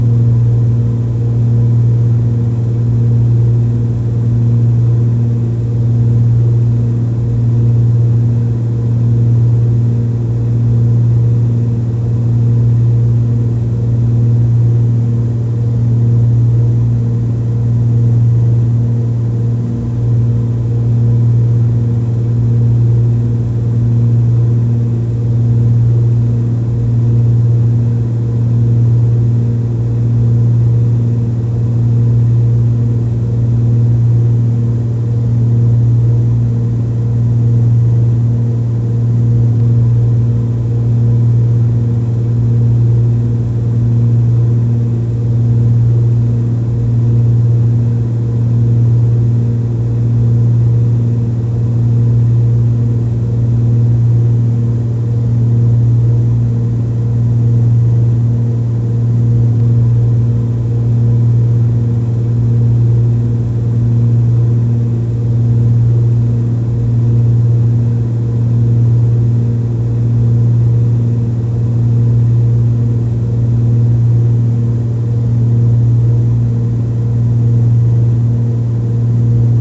Airplane.wav